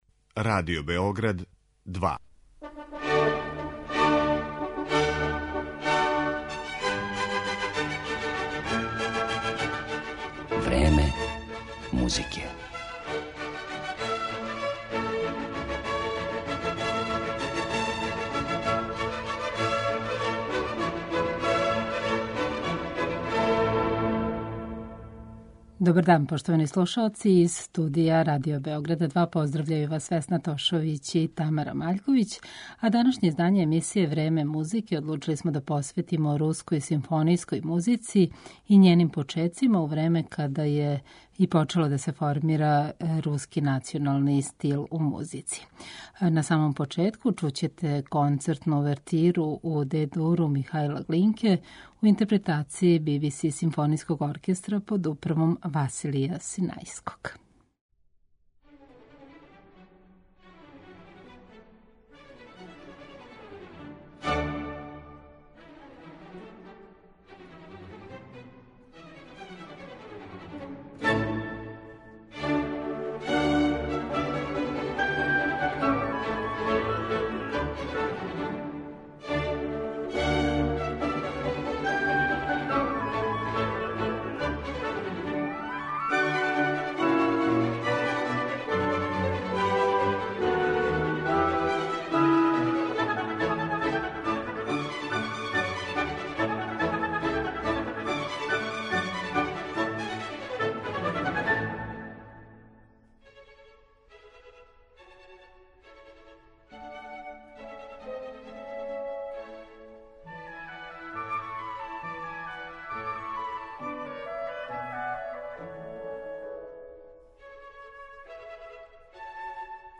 Руска симфонијска музика